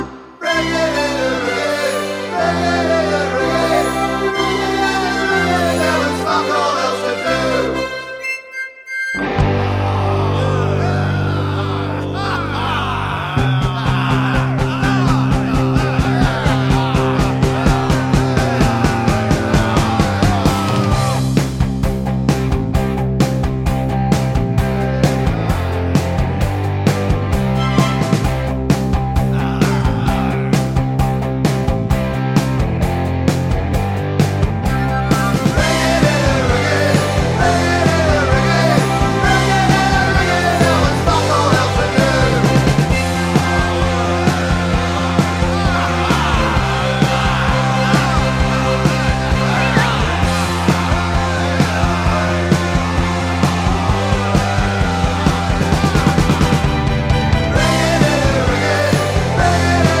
no Backing Vocals Punk 2:45 Buy £1.50